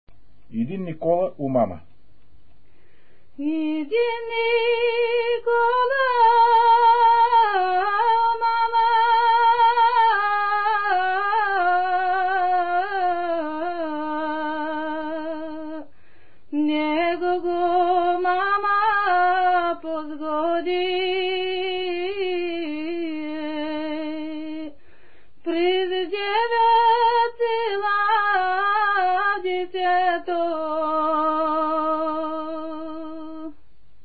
музикална класификация Песен
тематика Хайдушка
размер Безмензурна
фактура Едногласна
начин на изпълнение Солово изпълнение на песен
фолклорна област Югоизточна България (Източна Тракия с Подбалкана и Средна гора)
място на записа Равна гора
начин на записване Магнетофонна лента